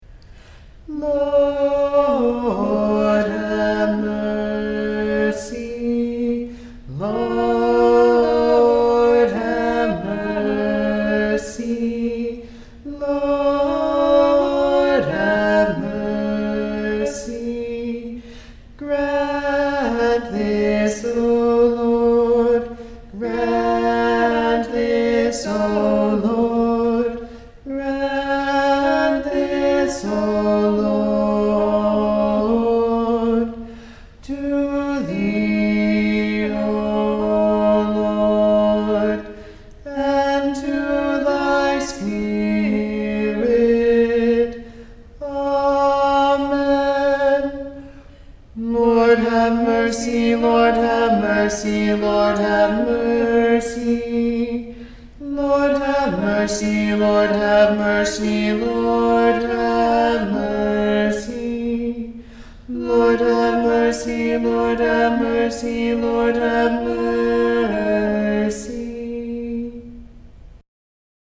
Annunciation, 2-Part (Sheet Music //